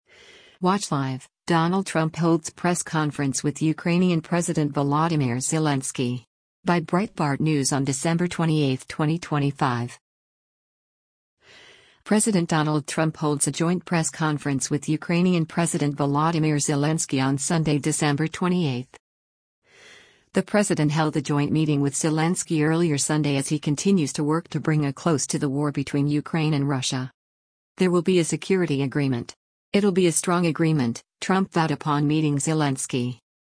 President Donald Trump holds a joint press conference with Ukrainian President Volodymyr Zelensky on Sunday, December 28.